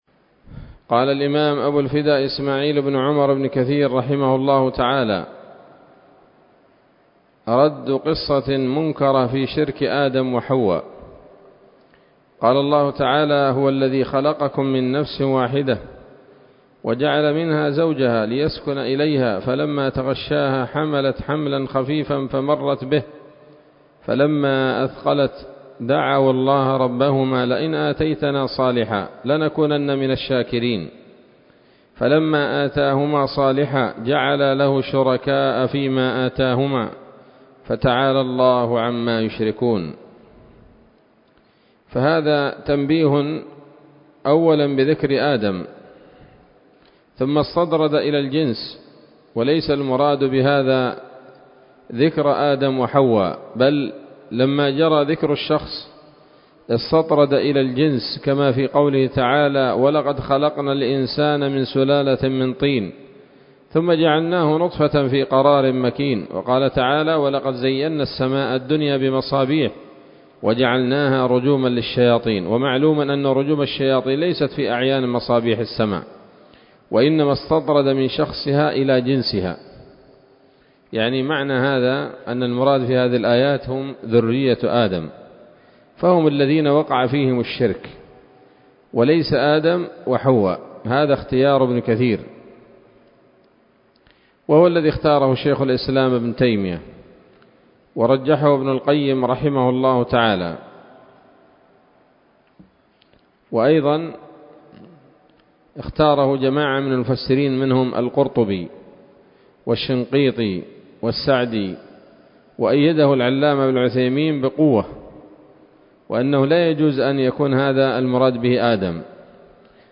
الدرس السابع عشر من قصص الأنبياء لابن كثير رحمه الله تعالى